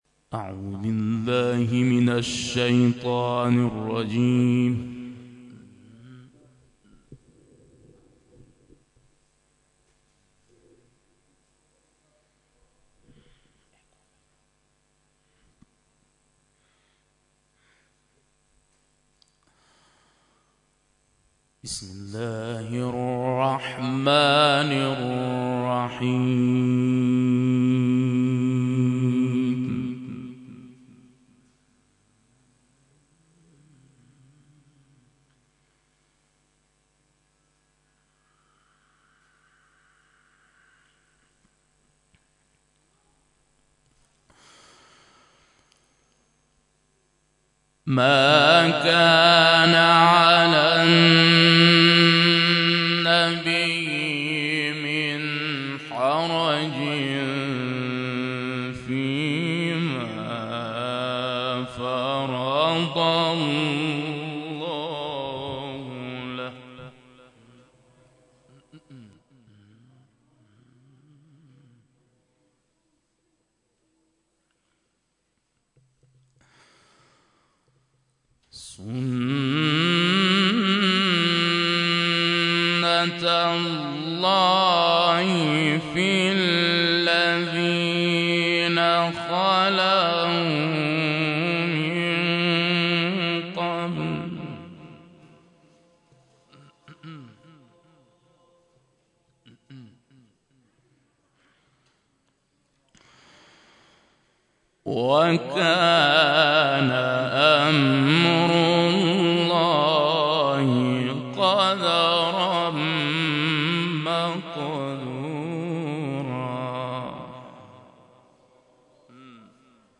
برگزاری محفل انس با قرآن در حسینیه یزدی‌ها + صوت و عکس
روز گذشته همزمان با سالروز ولادت امام حسن مجتبی(ع) حسینیه یزدی ها میزبان محفل انس با قرآن با حضور قاری و حافظ بین‌المللی کشور بود.